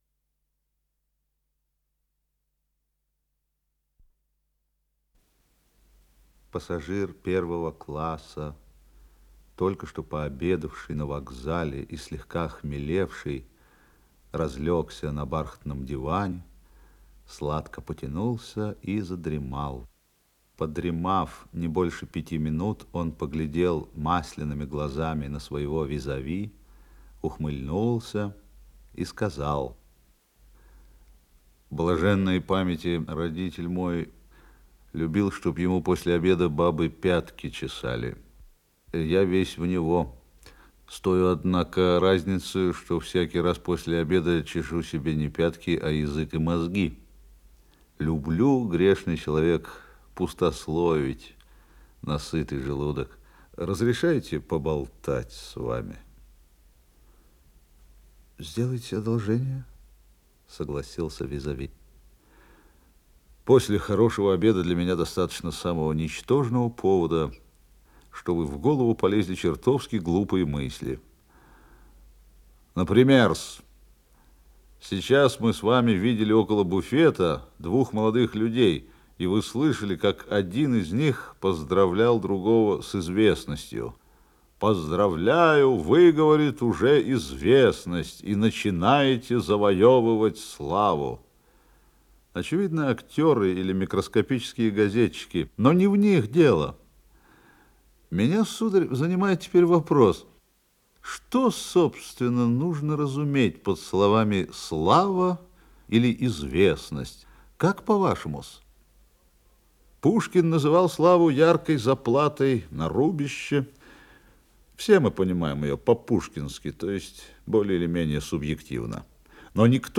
Исполнитель: Сергей Юрский - чтение
Рассказ